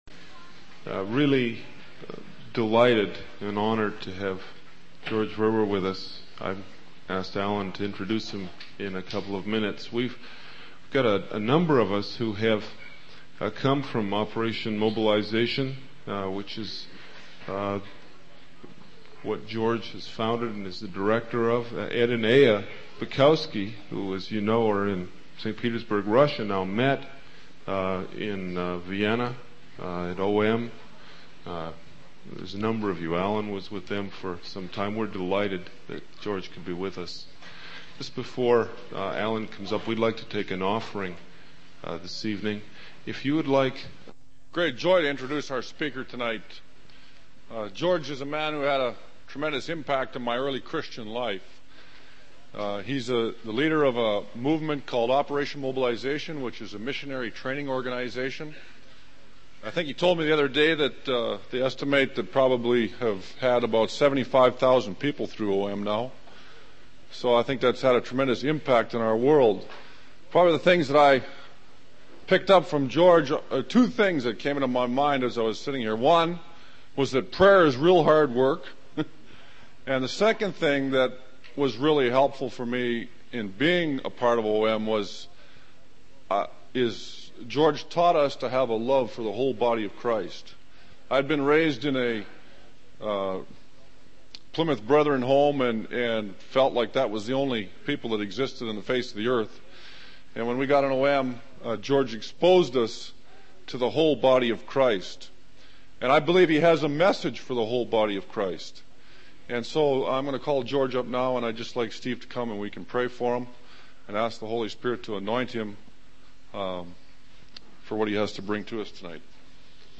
In this sermon, the speaker emphasizes the importance of little things in the life of a Christian. He shares examples of how he personally engages in small acts of kindness and evangelism, such as making phone calls, writing letters, and giving out tracks and books.